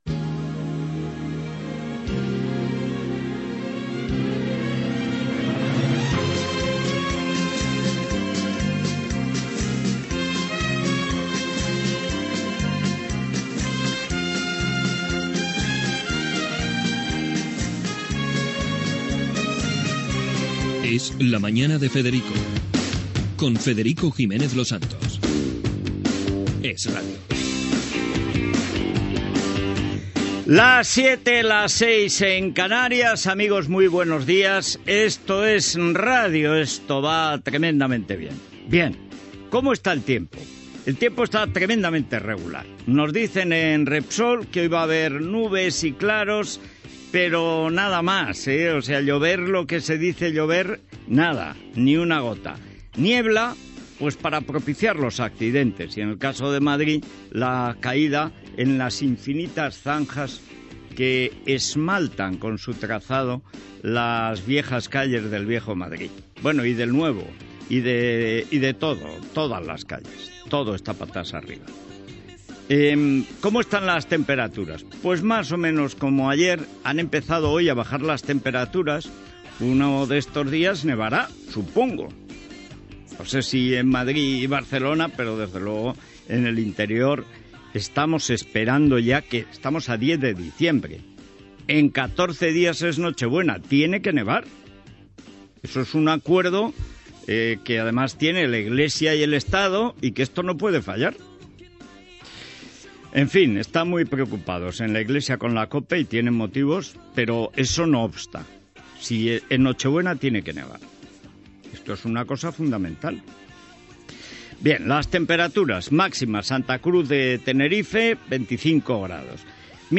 Indicatiu del programa, portada de les 07:00 del matí. El temps, comentaris sobre el judici a Sánchez Manzano, les opinions del president Rodríguez Zapatero, publicitat, indicatiu del programa, represà del judici a Sánchez Manzano
Info-entreteniment
FM